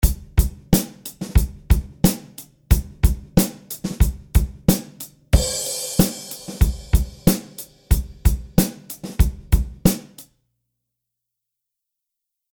Dans tous les exemples suivants, c’est la même partie de batterie est qui est utilisée.
Les deux exemples suivants sont créés avec la IR-L de Waves et sont respectivement les convolutions de la Wembley Arena de Londres et du mythique club CBGB de New-York :
Drums + CBGB Reverb
Drums_CBGB-Club.mp3